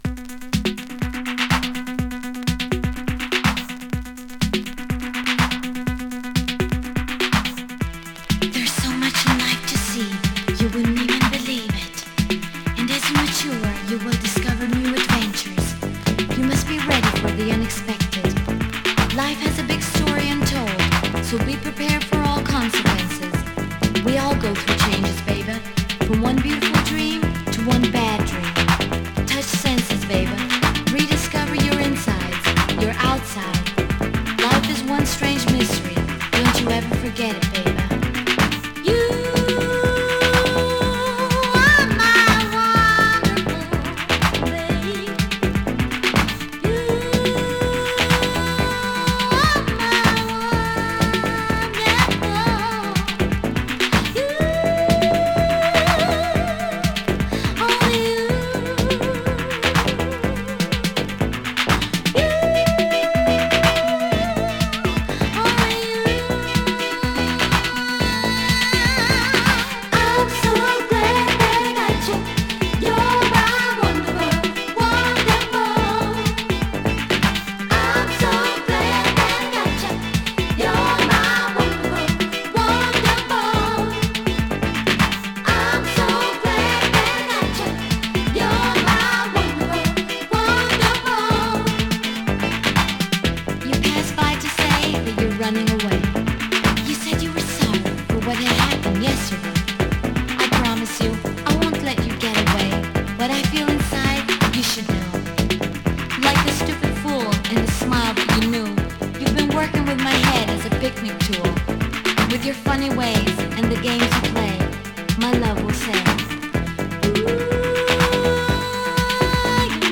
media(A/B): VG/VG 薄いスリキズによる若干のチリノイズ、レーベルに書き込み
Garage Classic！
【ITALO DISCO】